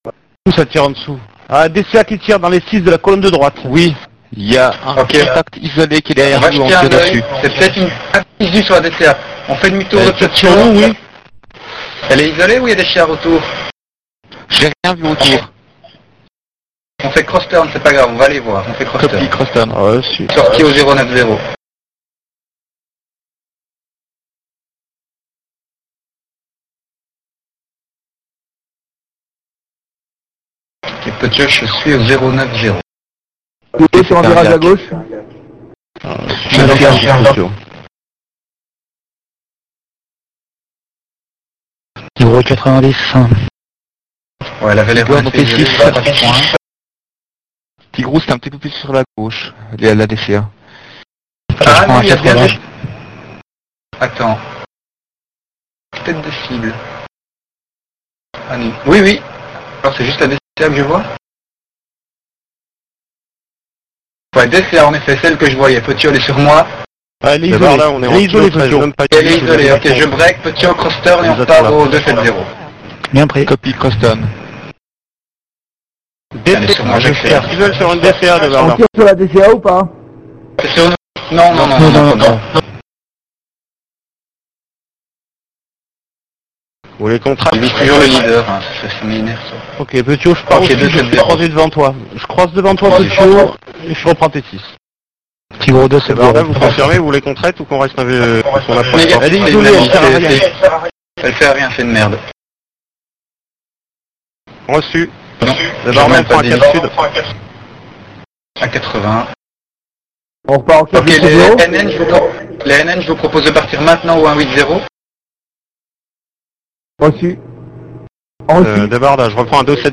L'ambiance CF IV
Voici quelques minutes d'une mission de CF IV, assez représentative de ce qui se passe hors gros combats impliquant toute une formation.